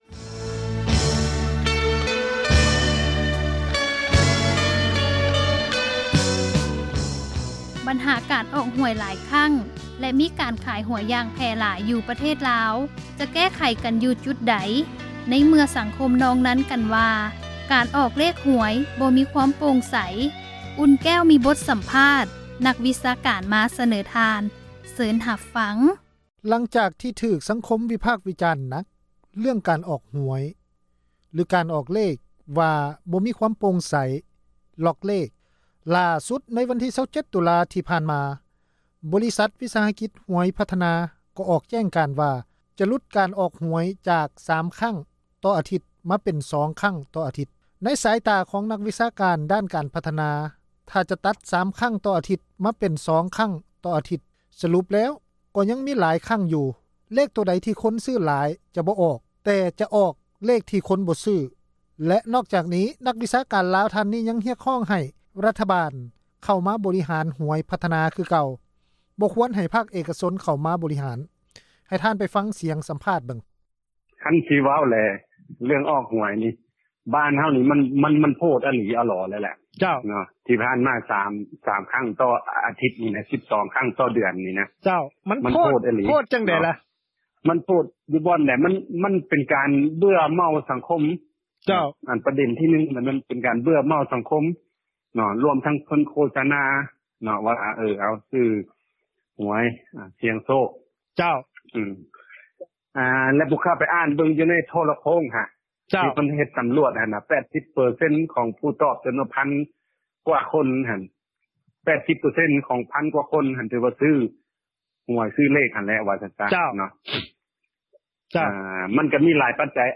ມີບົດ ສຳພາດ ນັກ ວິຊາການ ມາສະເໜີ ທ່ານ.